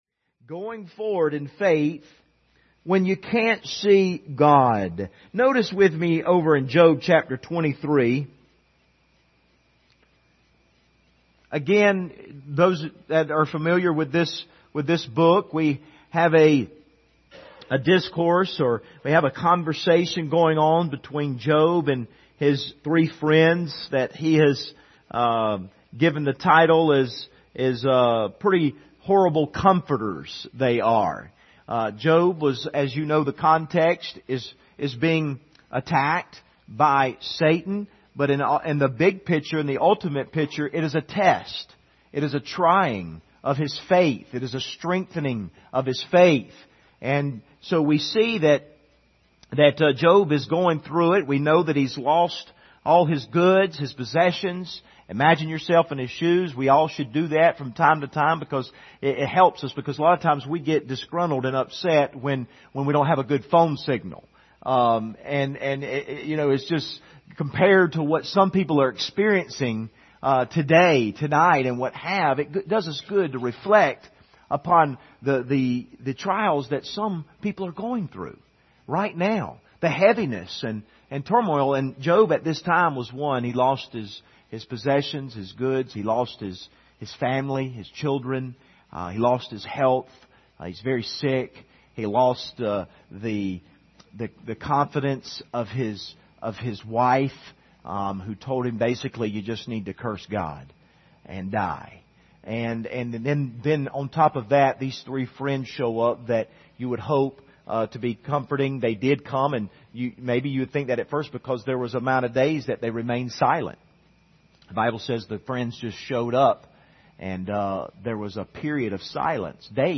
General Passage: Romans 8:28, Job 23:1-12 Service Type: Sunday Evening « How Do You Plead?